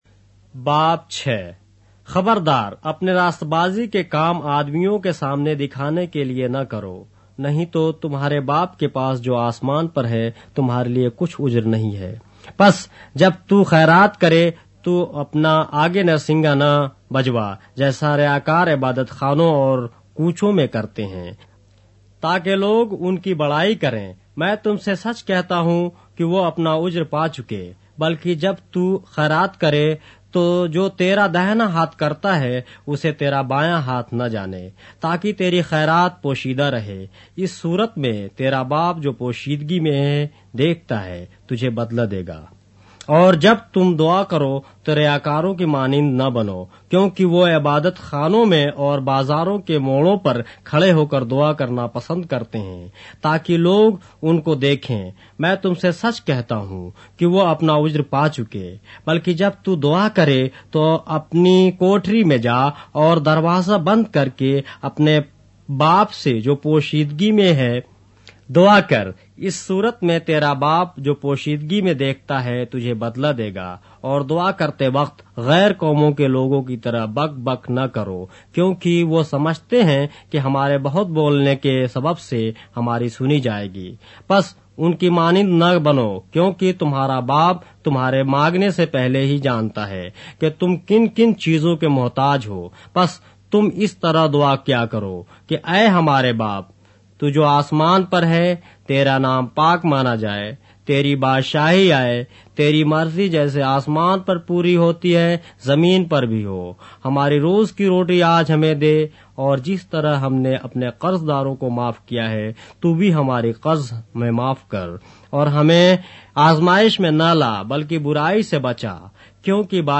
اردو بائبل کے باب - آڈیو روایت کے ساتھ - Matthew, chapter 6 of the Holy Bible in Urdu